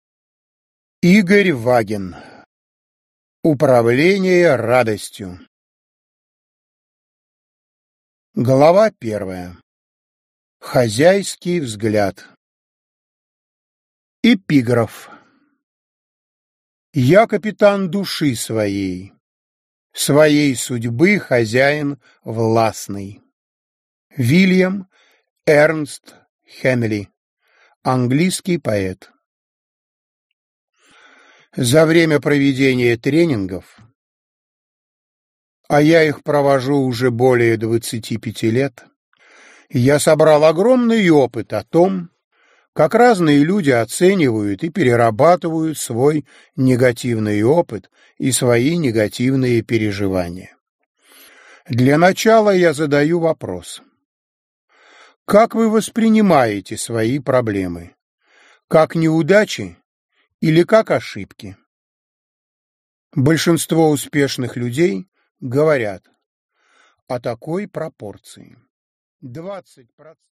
Аудиокнига Управление радостью | Библиотека аудиокниг